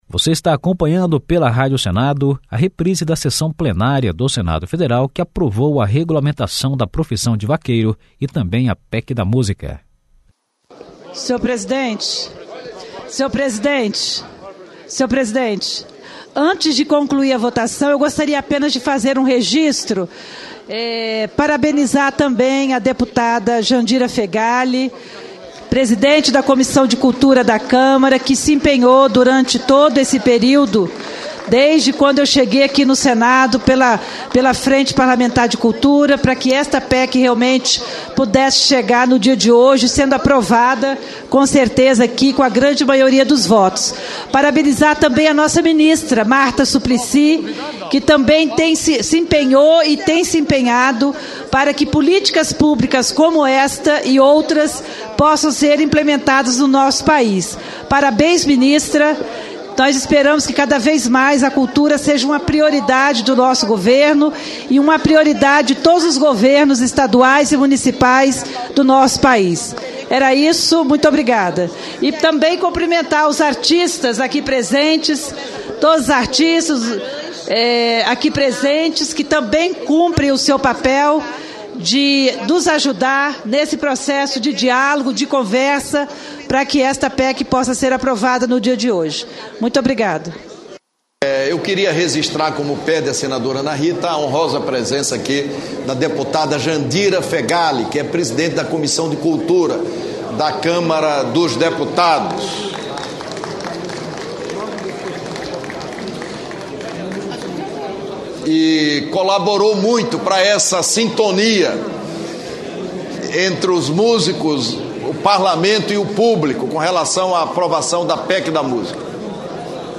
Sessão de aprovação da PEC da Música e do PL da profissão de vaqueiro (final)